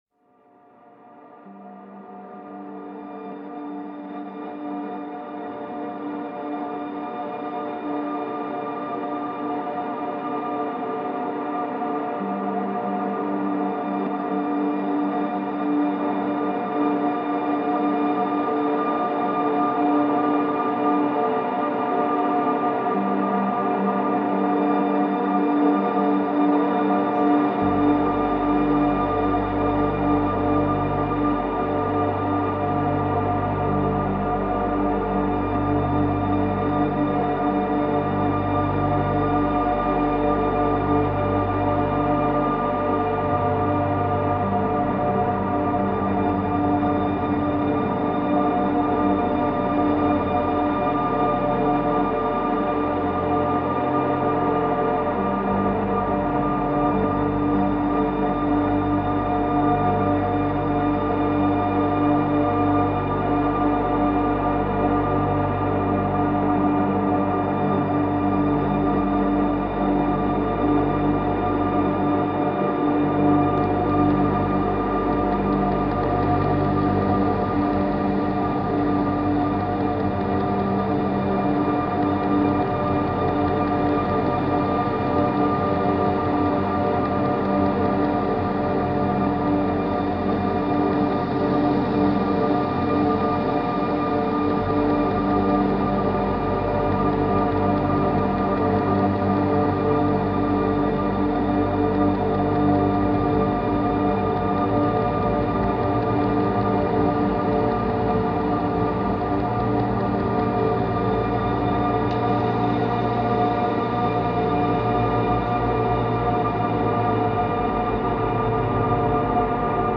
Dark Drone Version